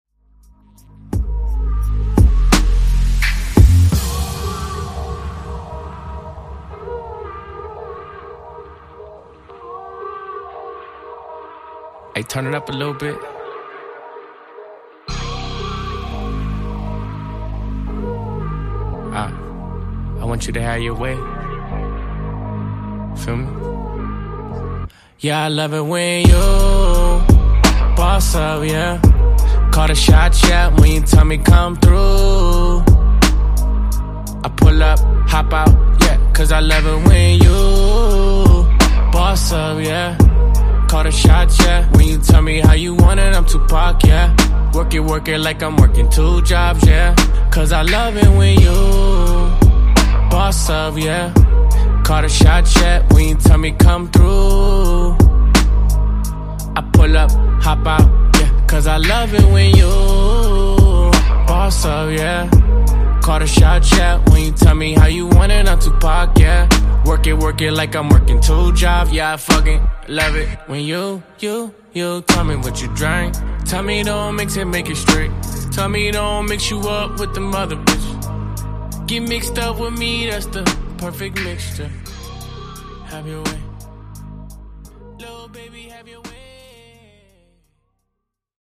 Genres: DANCE , RE-DRUM Version: Clean BPM: 130 Time